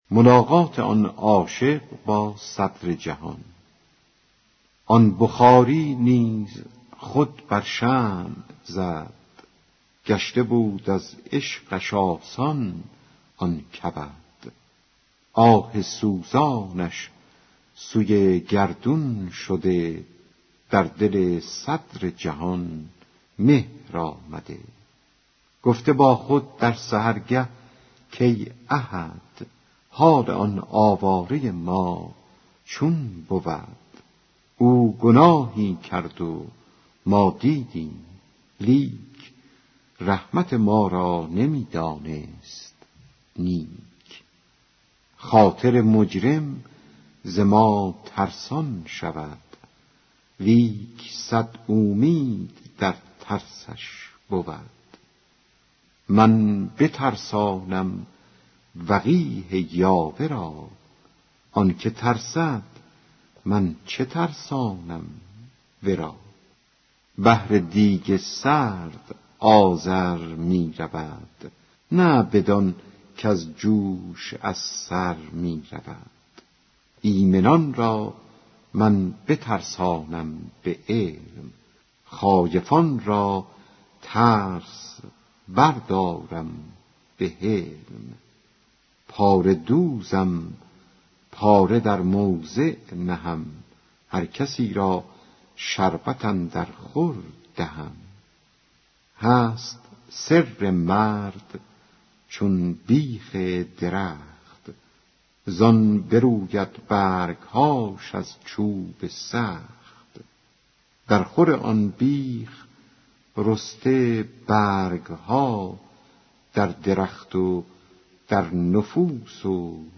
دکلمه ملاقات آن وکیل عاشق بخارایی با صدر جهان